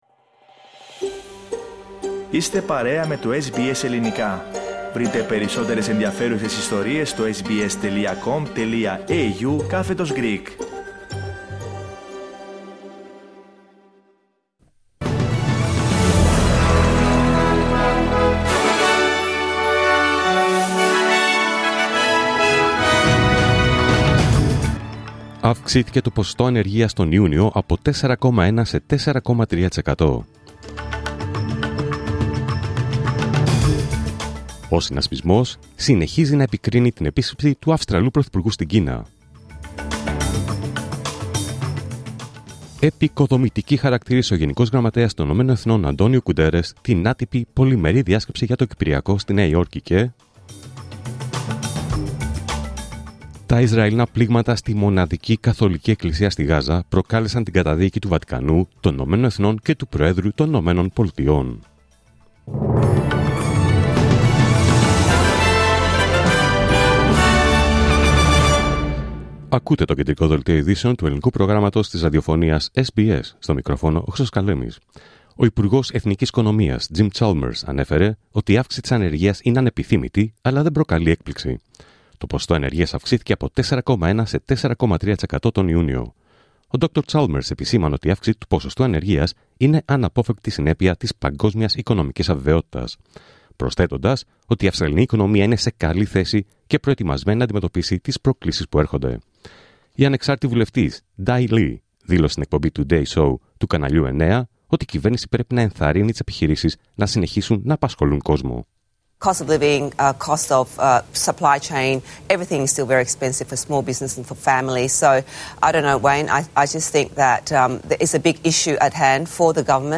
Δελτίο Ειδήσεων Παρασκευή 18 Ιουλίου 2025
News in Greek.